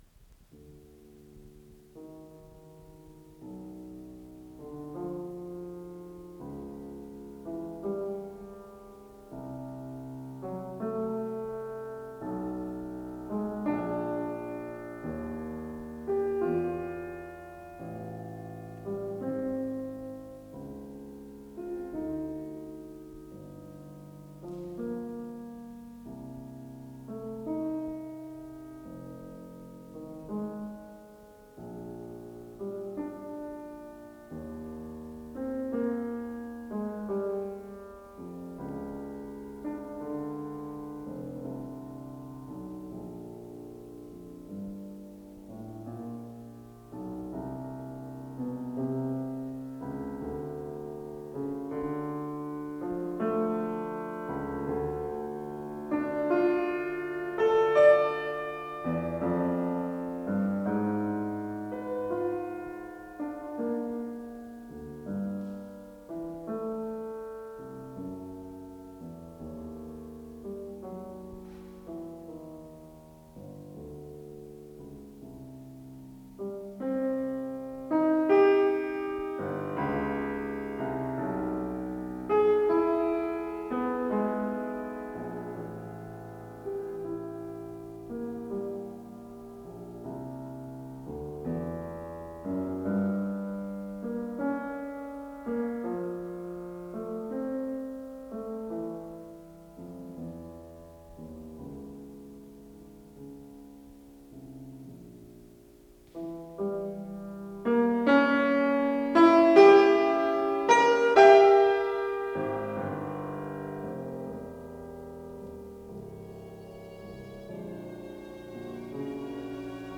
ПодзаголовокСи минор
Соло на фортепиано
ВариантДубль моно